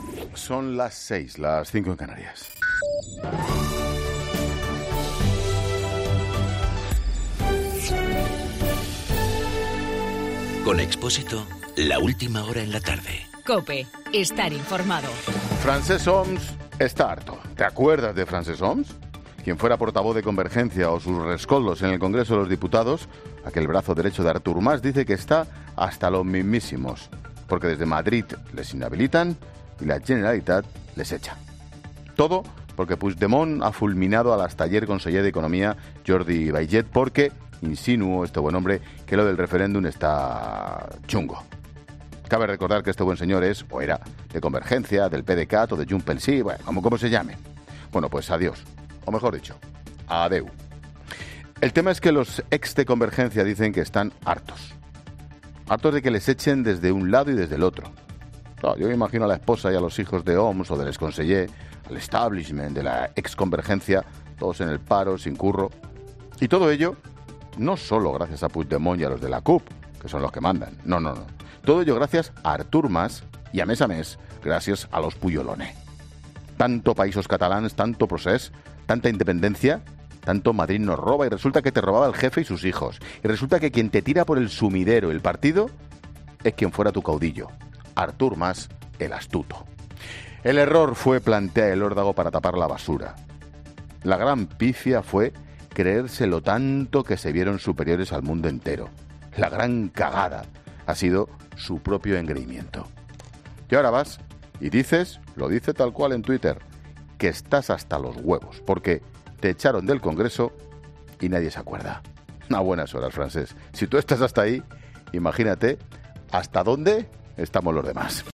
AUDIO: Monólogo de Ángel Expósito a las 18h.